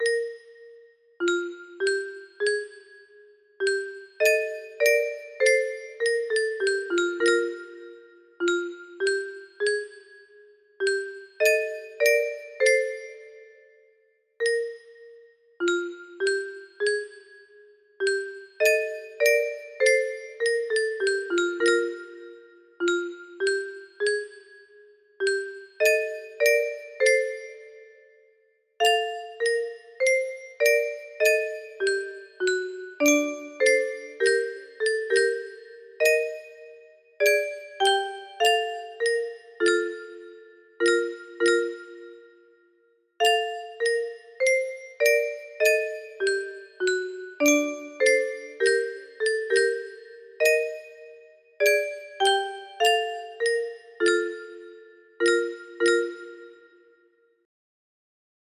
A slow and melancholy music box cover of Bach's second "Minuet", as played by the Epson SVM7910E Melody IC.